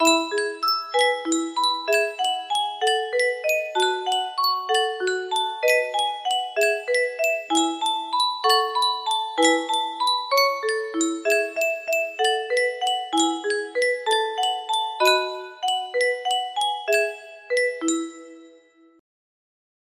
Yunsheng Music Box - Humpty Dumpty Y092 music box melody
Full range 60